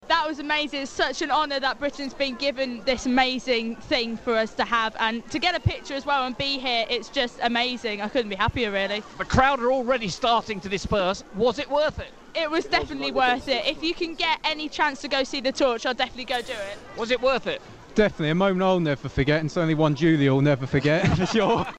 Next The scene outside All Saints Church, Npton - torch on it's way!